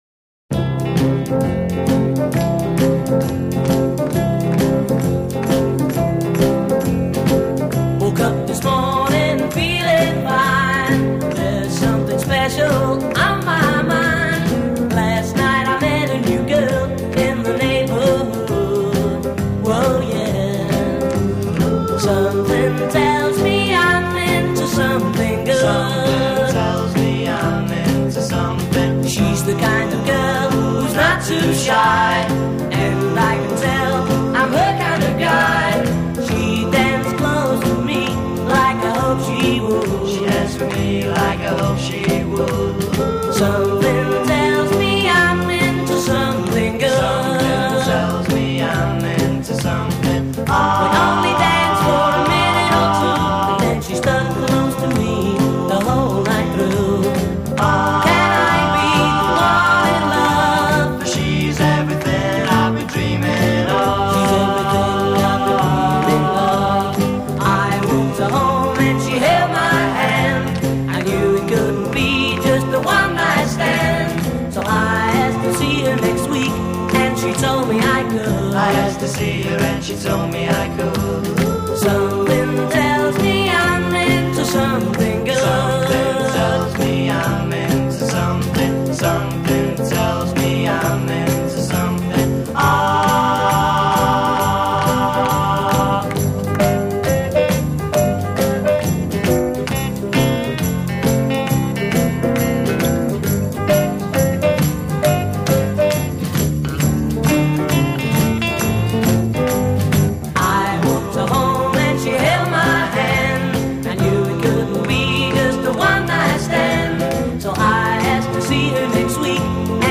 bass guitar
refrain : 8 solo vocal with responding chorus on hook b
A verse : 16 solo vocal with harmony at phrase ends c
B chorus : 19 vocal solo and chorus building to end of line
refrain : 32+ repeat refrain and fade b